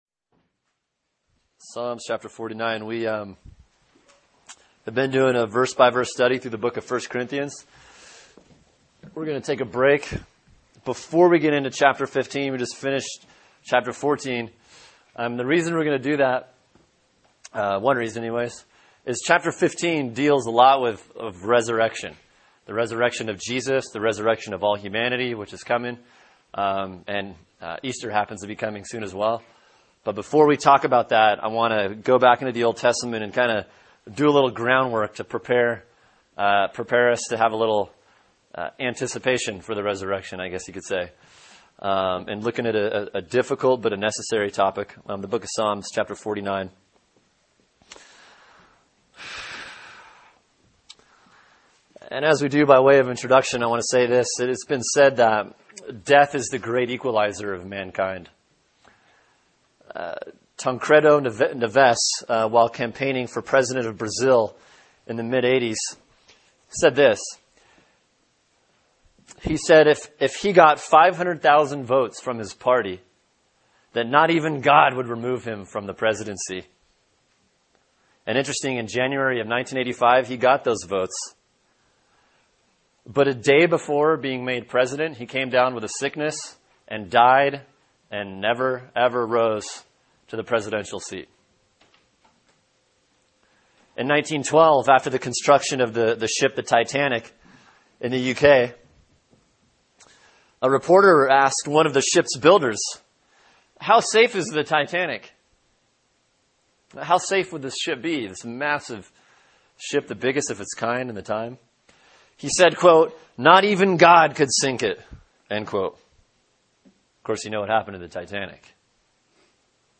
Sermon: Psalm 49 “Reality Check” | Cornerstone Church - Jackson Hole